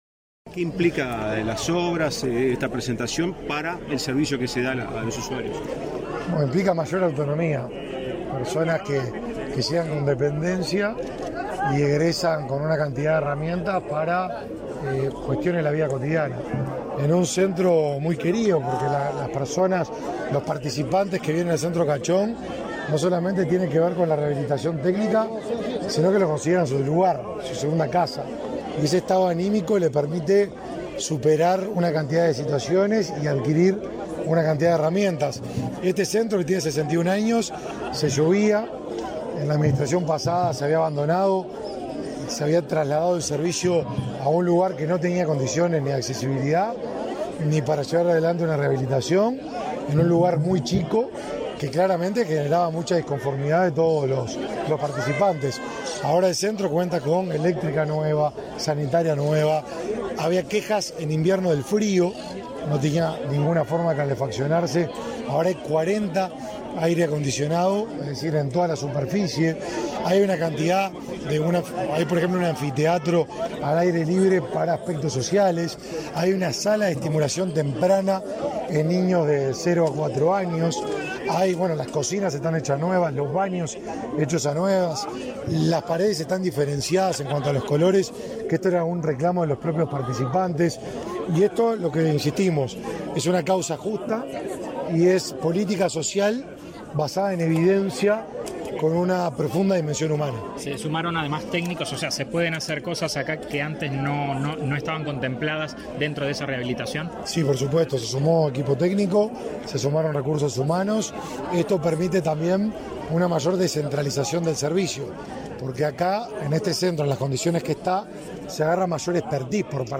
Declaraciones a la prensa del ministro del Mides, Martín Lema
Declaraciones a la prensa del ministro del Mides, Martín Lema 15/02/2023 Compartir Facebook X Copiar enlace WhatsApp LinkedIn Con la presencia del presidente de la República, Luis Lacalle Pou, el Ministerio de Desarrollo Social (Mides) y el Ministerio de Transporte y Obras Públicas (MTOP) inauguraron, este 15 de febrero, las obras de remodelación y acondicionamiento del centro de rehabilitación para personas con discapacidad visual Tiburcio Cachón. Tras el evento, el ministro Martín Lema realizó declaraciones a la prensa.